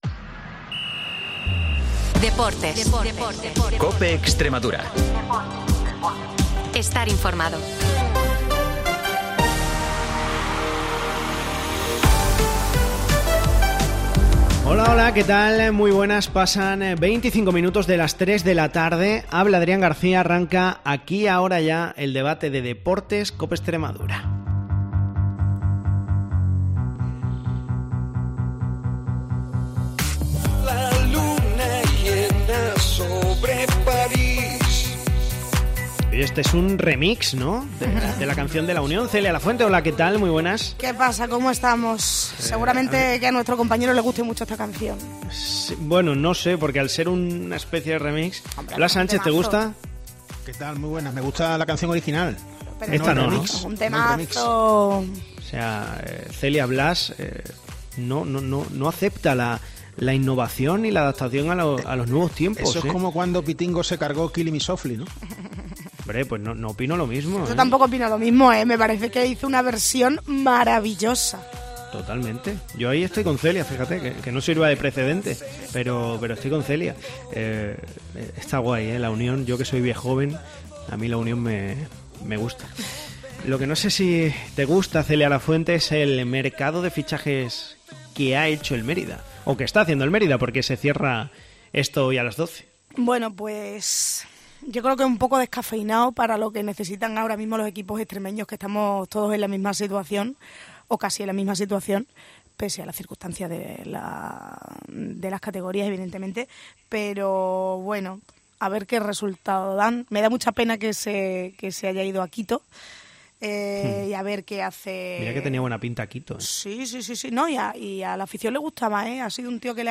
El debate de deportes de COPE Extremadura